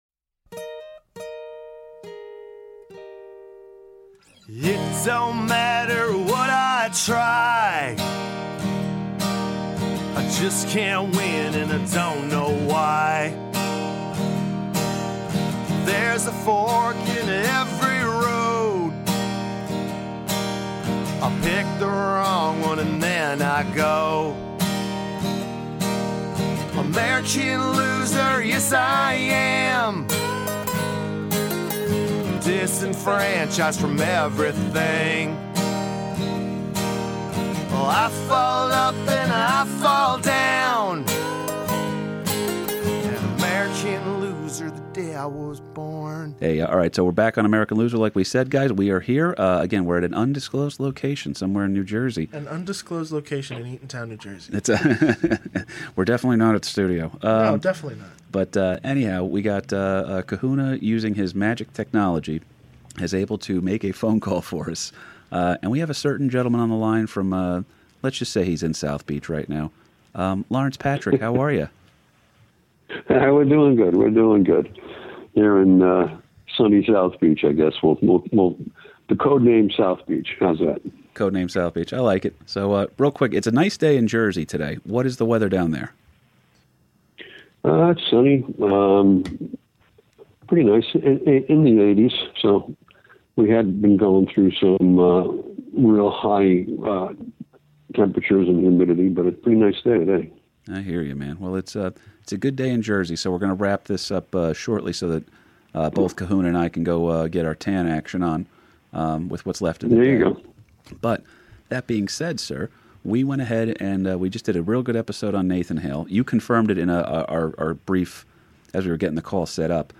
It’s a skype call so the quality isn’t the best but it’ll have to do til we can get him back up in Jersey.